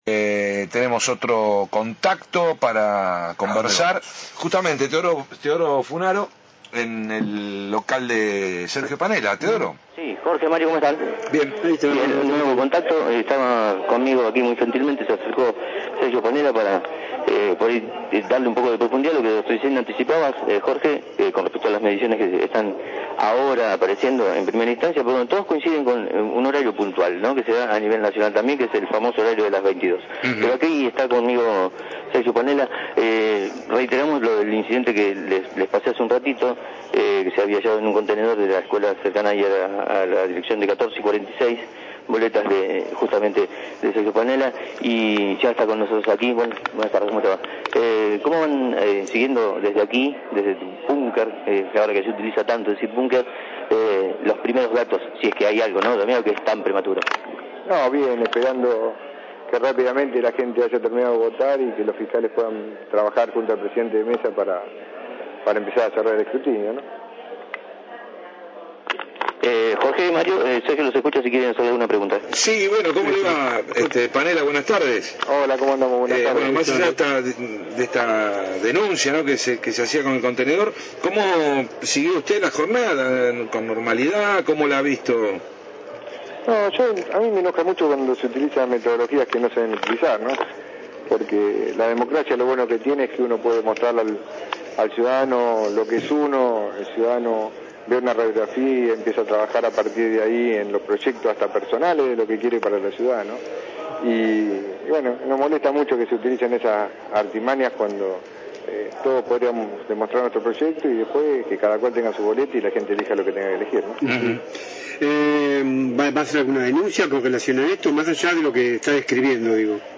en el búnker en el que aguarda los primeros resultados del escrutinio